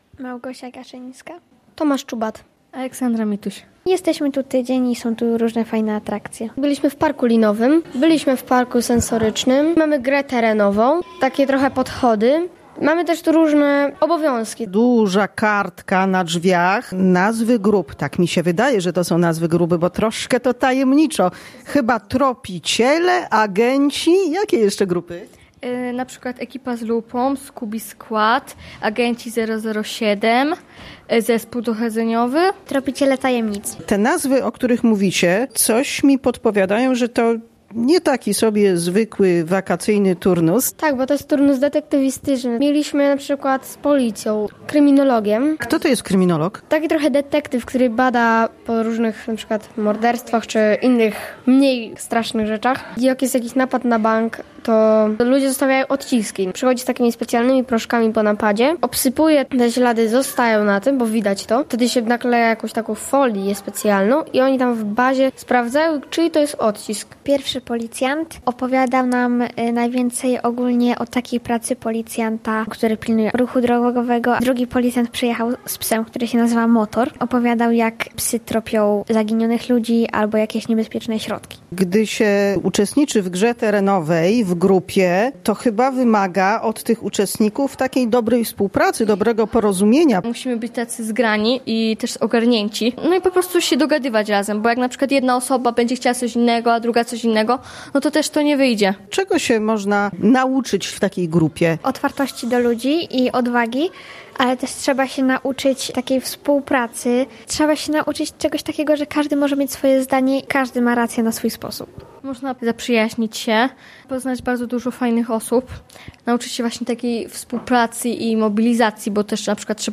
W magazynie katolickim rozmowy na temat wakacyjnego wyjazdu oraz budowaniu wspólnoty.